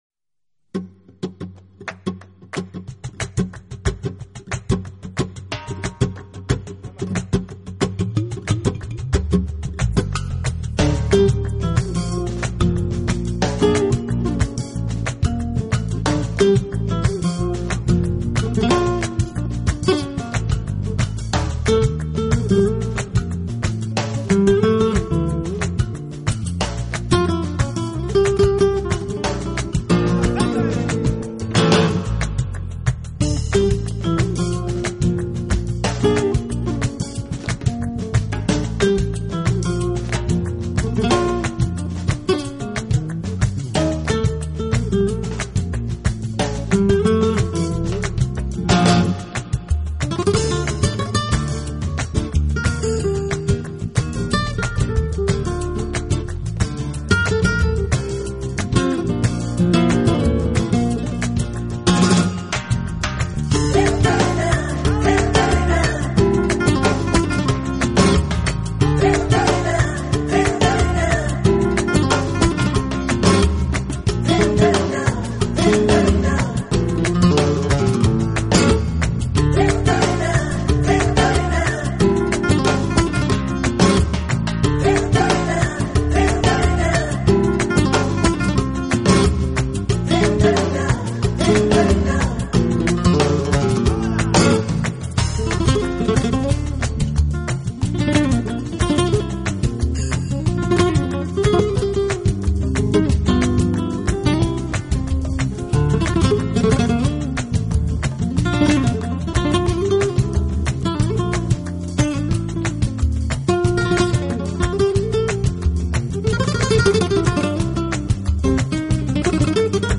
【吉他专辑】